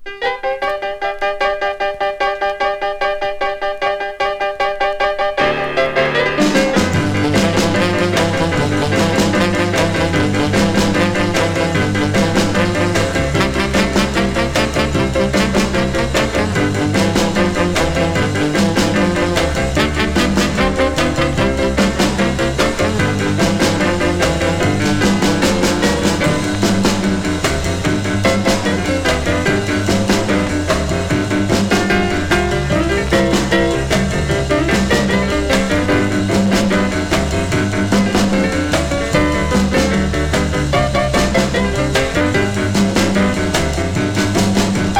Rhythm & Blues, Rock & Roll 　USA　12inchレコード　33rpm　Mono